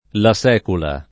[ la S$ kula ]